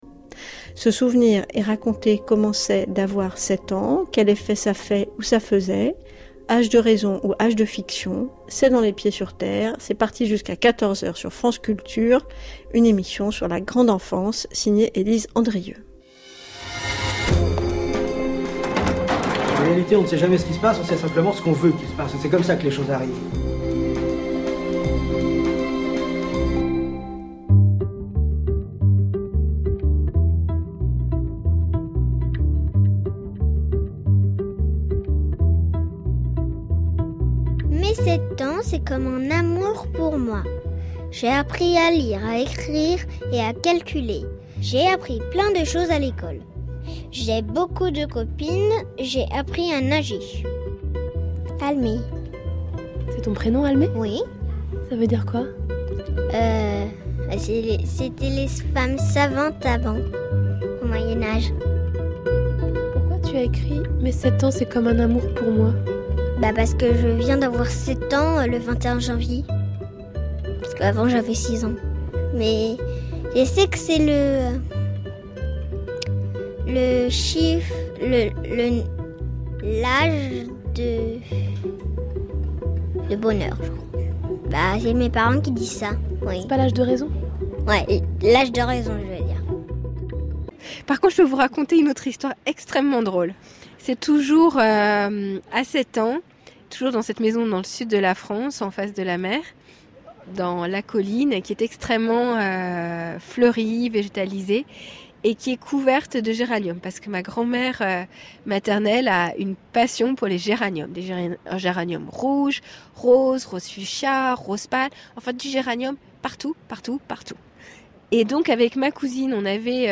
ÉMISSION DE RADIO (RNS1)